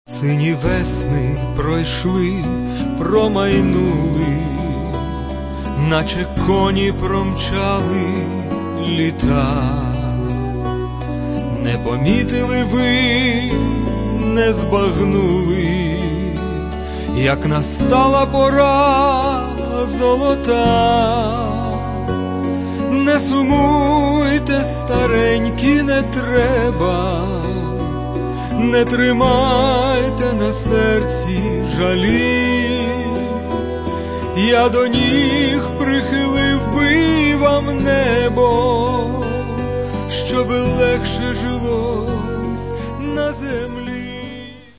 Каталог -> Естрада -> Співаки